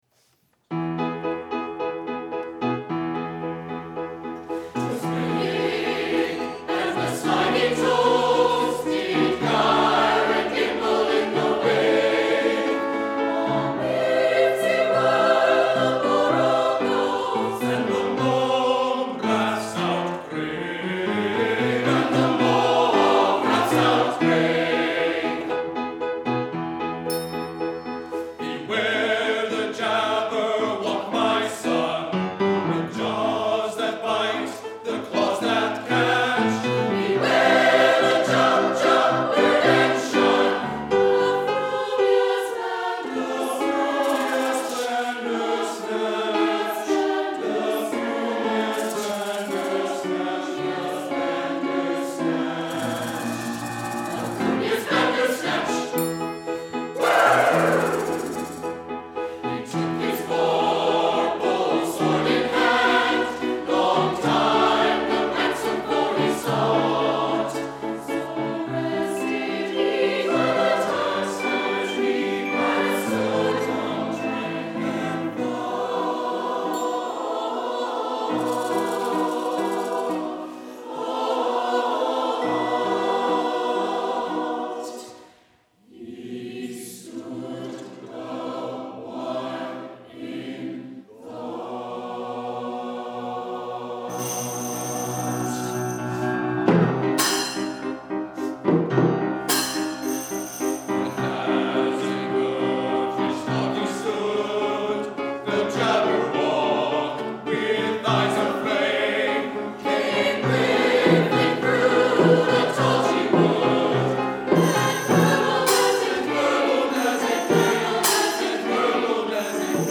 Below you will find select audio and video from our past performances for your listening and viewing enjoyment.
From our January 30, 2016 concert, Journeys: